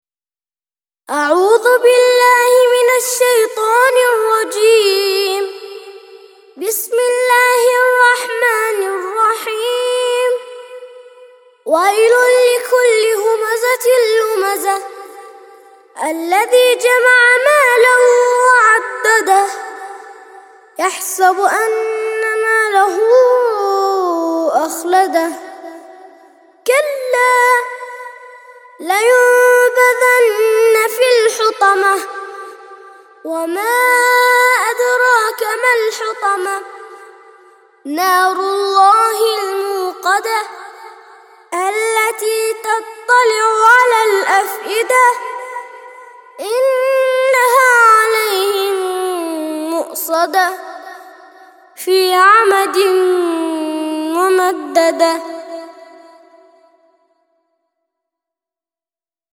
104- سورة الهمزة - ترتيل سورة الهمزة للأطفال لحفظ الملف في مجلد خاص اضغط بالزر الأيمن هنا ثم اختر (حفظ الهدف باسم - Save Target As) واختر المكان المناسب